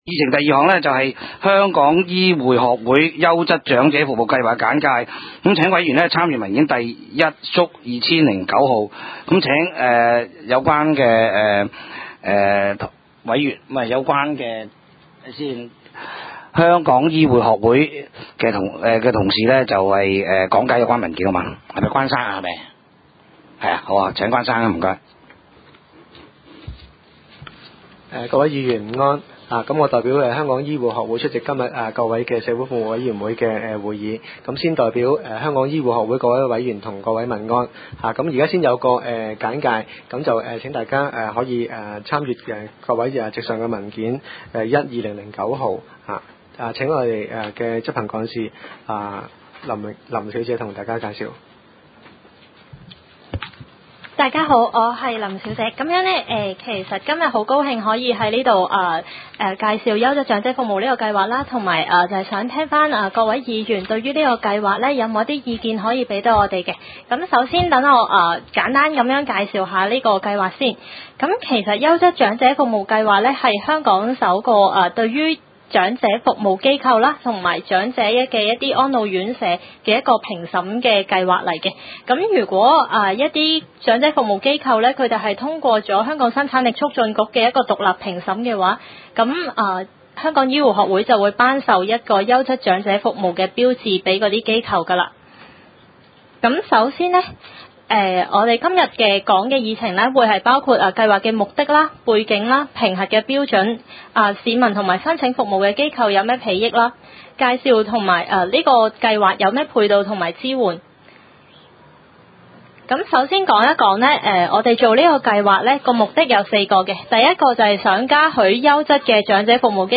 第三屆觀塘區議會屬下 社會服務委員會第九次會議記錄 日 期 : 2009 年 2 月 10 日 ( 星期四 ) 時 間 : 下午 2 時 30 分 地 點 : 九龍觀塘同仁街 6 號觀塘政府合署 3 樓觀塘民政事務處會議室 議 程 討論時間 I. 通過上次會議記錄 0:01:01 II.